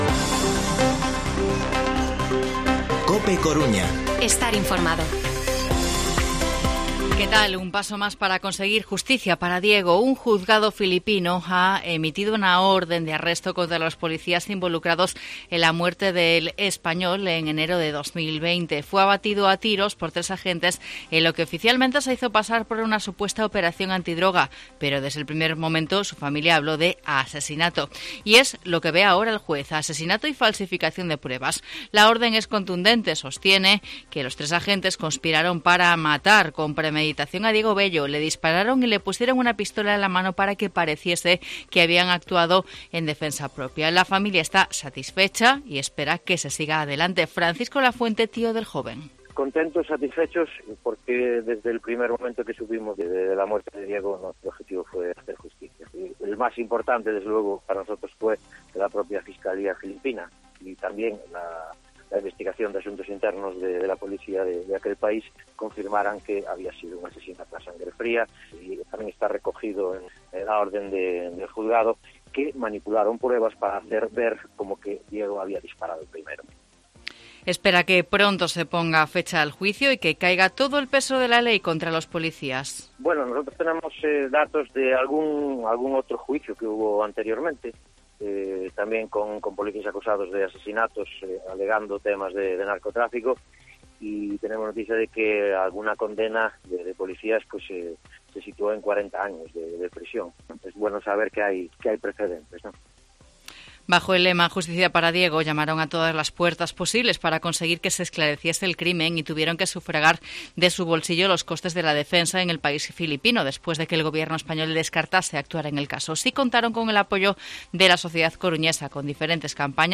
Informativo Mediodía COPE Coruña jueves, 21 de abril de 2022 14:20-14:30